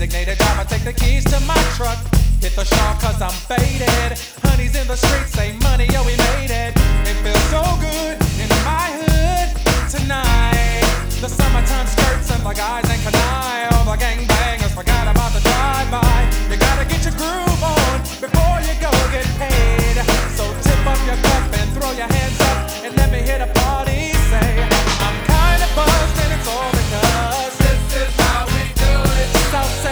• Hip-Hop/Rap